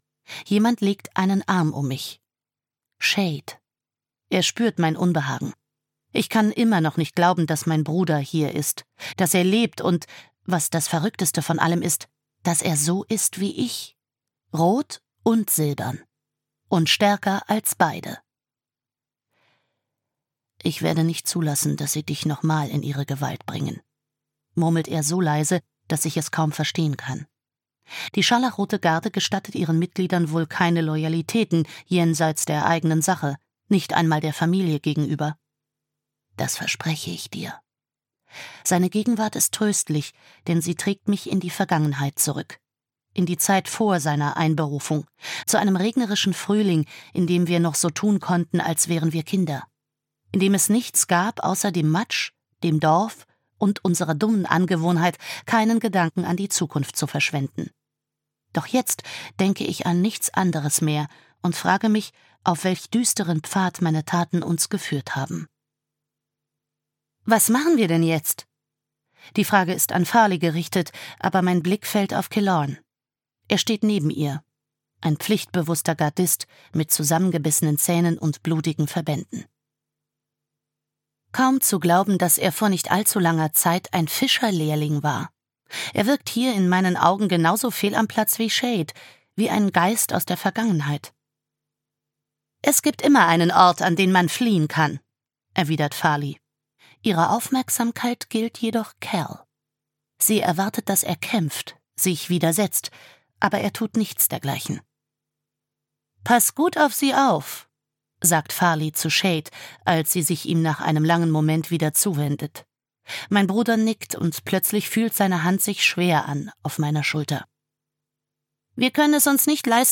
Gläsernes Schwert (Die Farben des Blutes 2) - Victoria Aveyard - Hörbuch